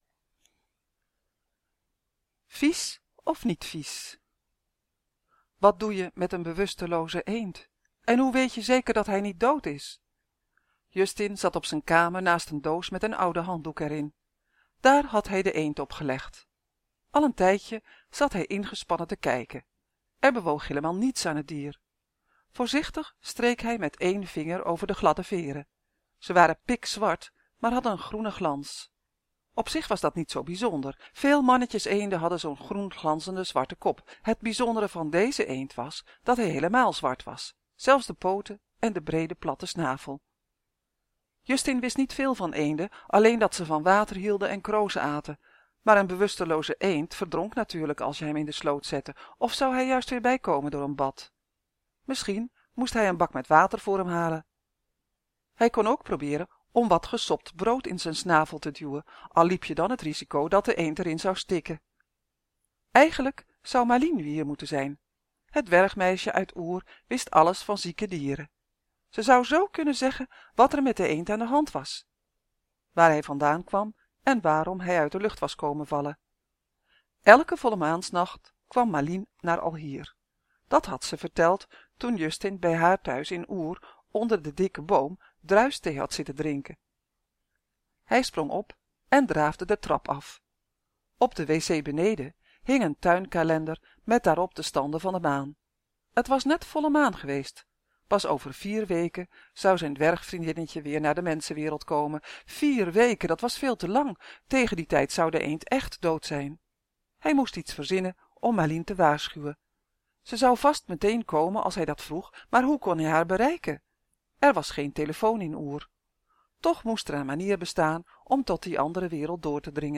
Het verhaal begint dan vanzelf!